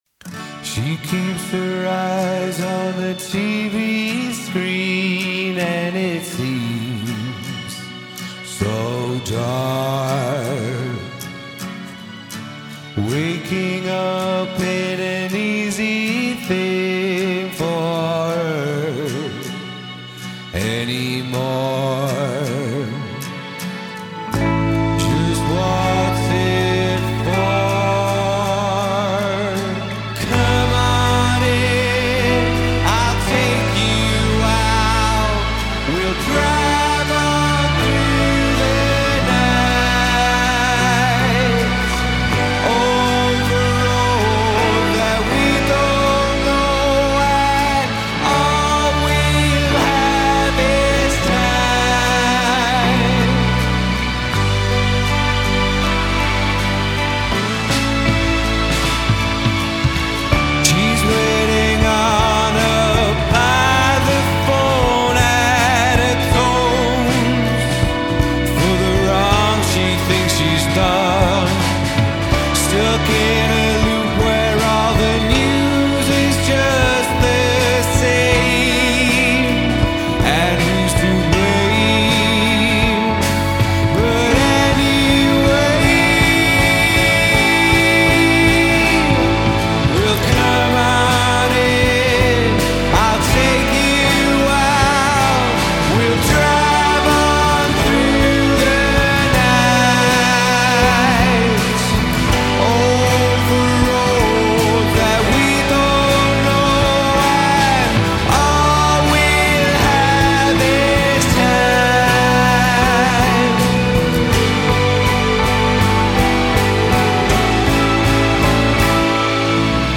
lead vocals
lead guitar
drums
bass
keys